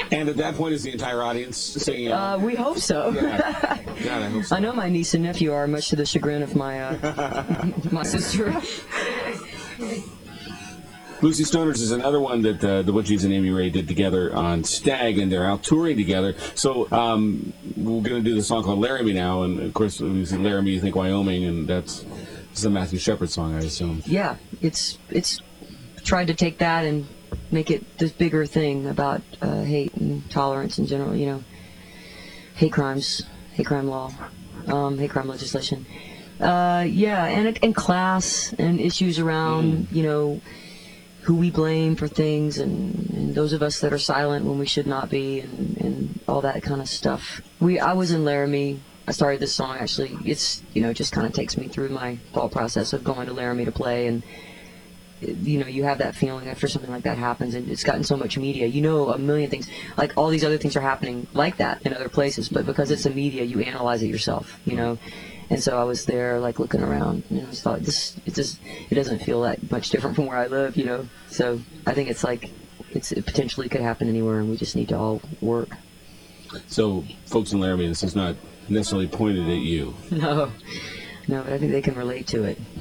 05. interview (1:22)